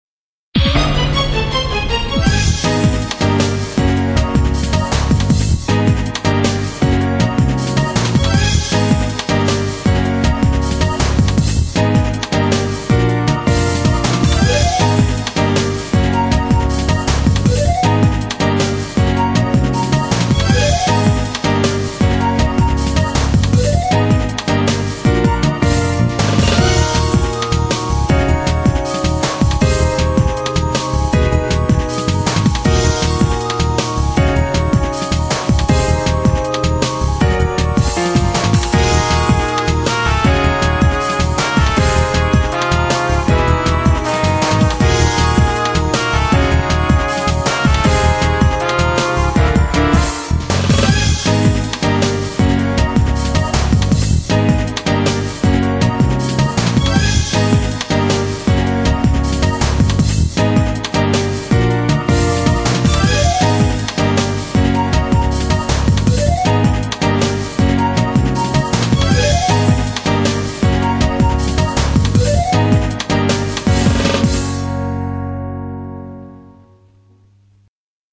音質下げてます、ご了承下さい。
イントロのアタックにショック感、後に危機が訪れる、といった流れ。